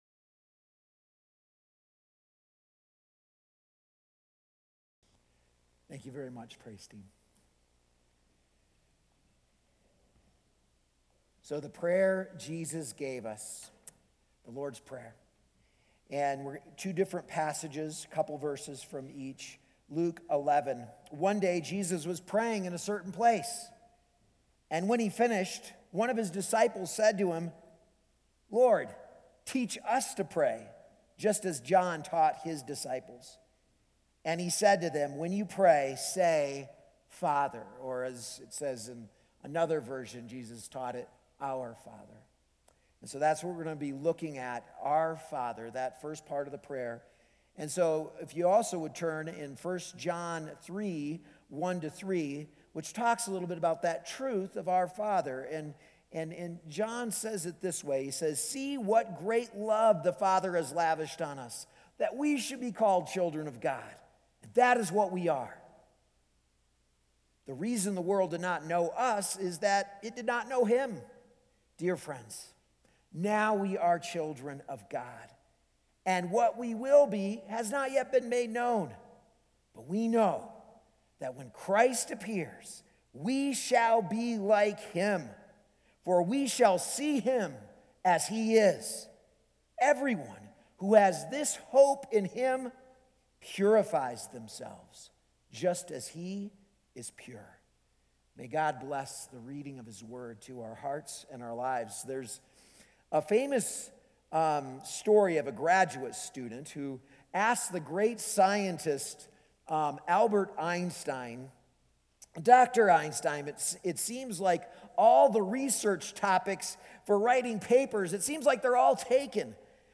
A message from the series "The Lord's Prayer."